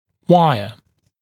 [‘waɪə][‘уайэ]проволока, ортодонтическая проволочная дуга